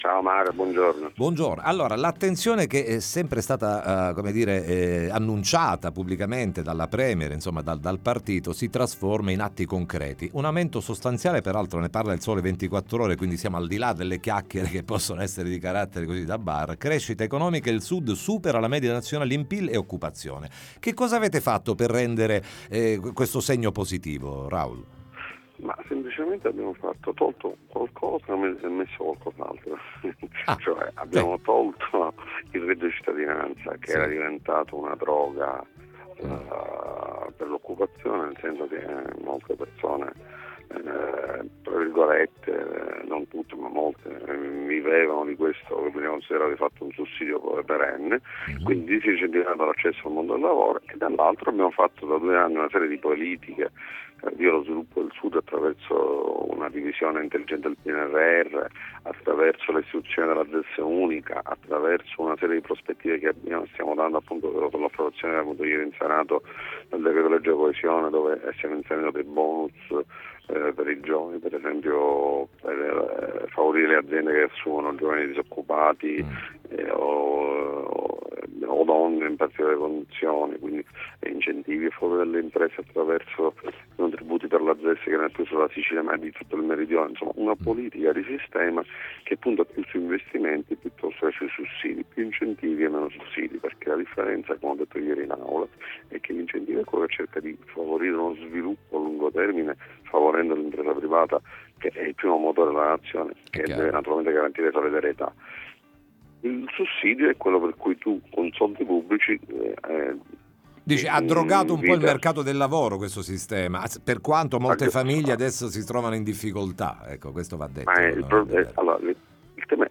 Aumento del PIL del Mezzogiorno, ne parliamo con il Sen. Raoul Russo